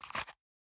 magpouch_replace_small.ogg